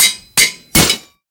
anvil_break.ogg